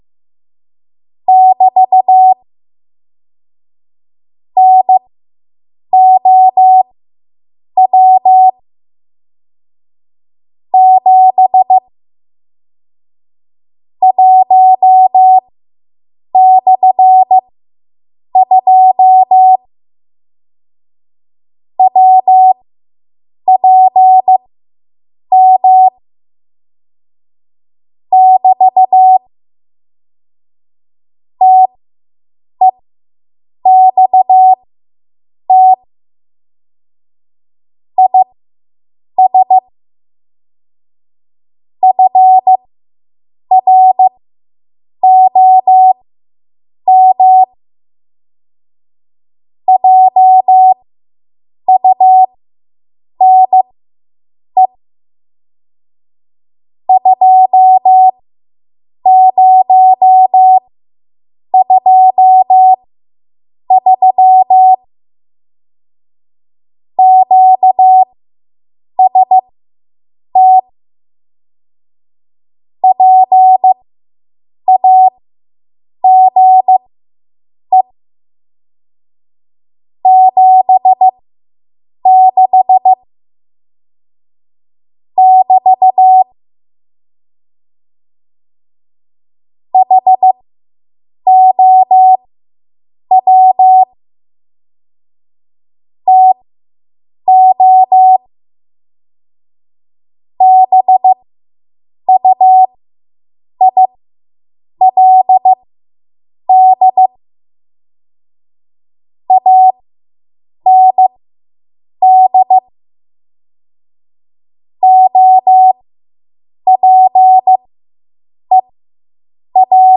7.5 WPM Code Practice Archive Files
Listed here are archived 7.5 WPM W1AW code practice transmissions for the dates and speeds indicated.
You will hear these characters as regular Morse code prosigns or abbreviations.